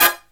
HIGH HIT05-R.wav